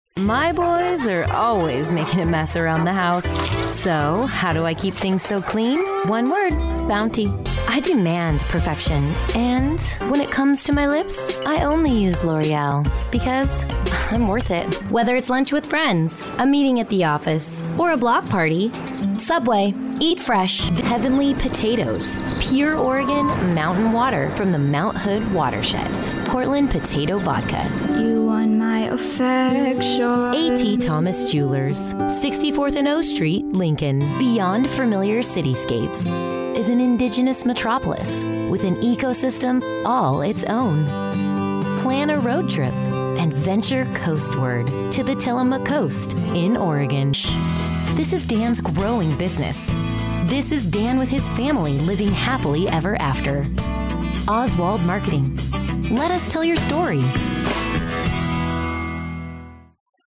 I am hip, modern, youthful and a ton of fun!
Commercial Voiceover, TV and Radio, Auto Dealer